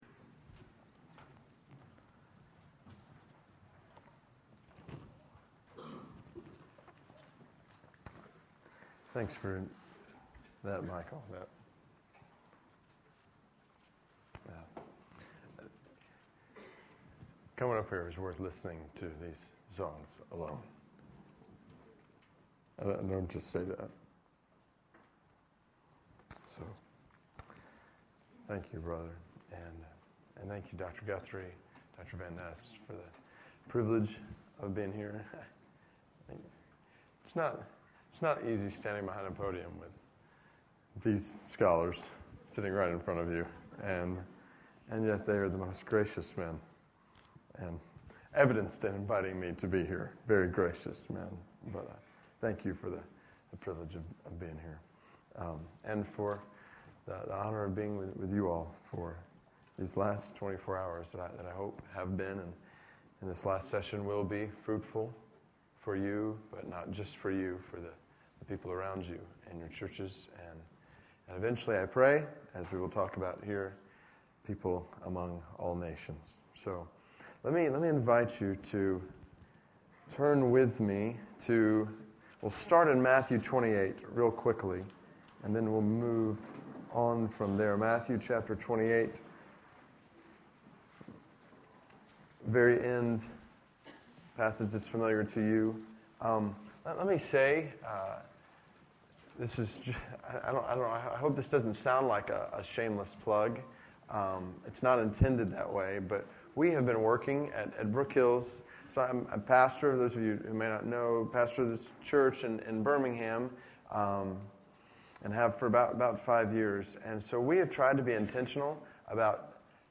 Read The Bible For Life Conference: David Platt - Plenary 3 David Platt , Pastor, Brook Hills Church, Birmingham, AL Address: The Bible Teachers Purpose...